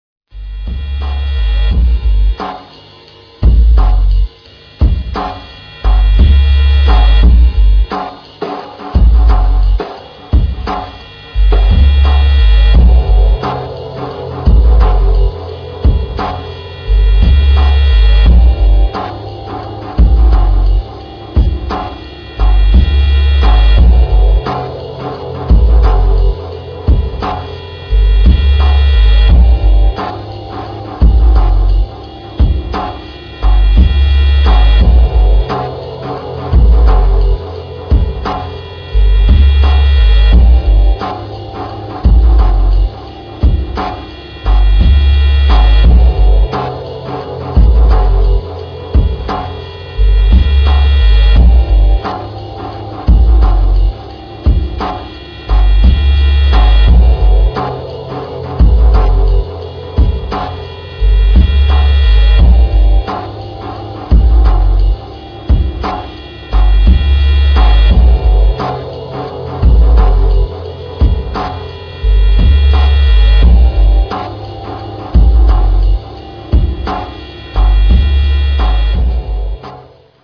INDUSTRIAL ELECTRÓNICO